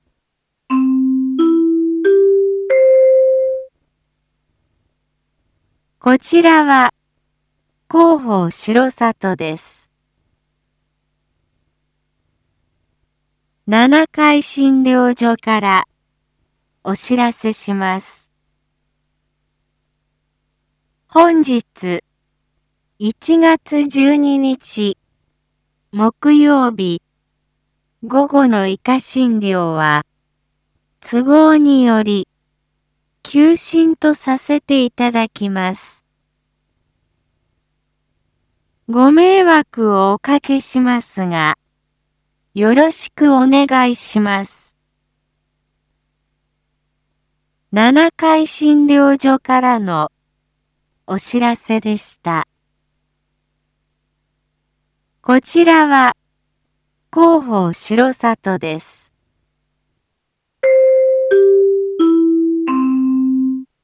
一般放送情報
Back Home 一般放送情報 音声放送 再生 一般放送情報 登録日時：2023-01-12 07:01:05 タイトル：R5.1.12 7時放送分 インフォメーション：こちらは広報しろさとです。